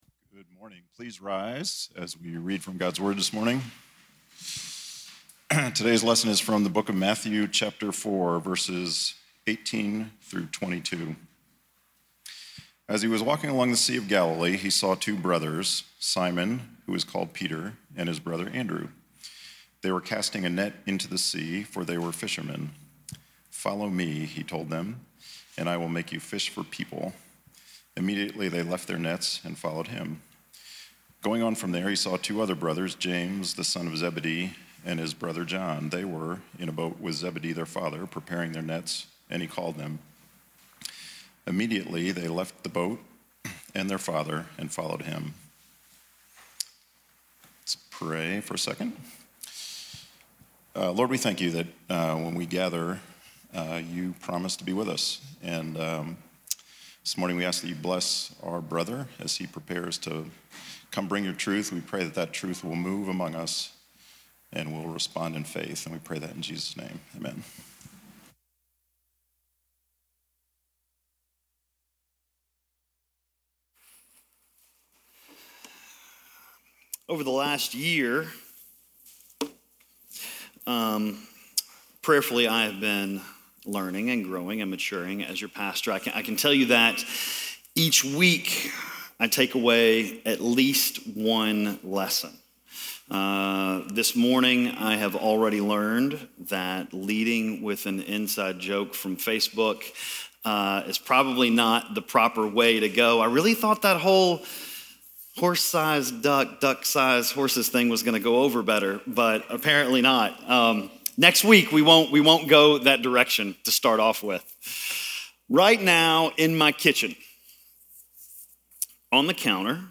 Who is a Disciple? - Sermon - Lockeland Springs